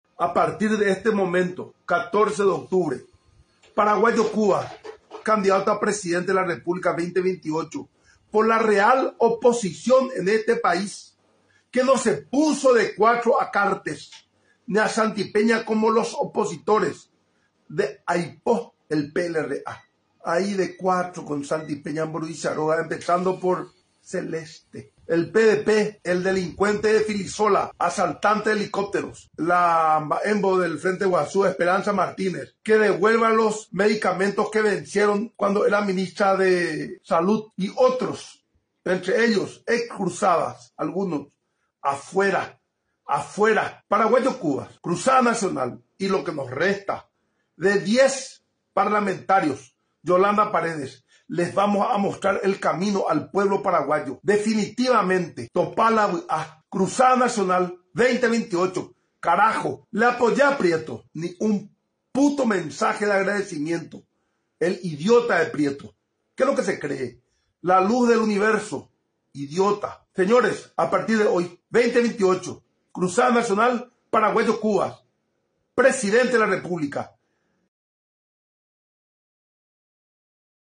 El tono de Cubas durante su relanzamiento fue, como es costumbre, agresivo y confrontativo.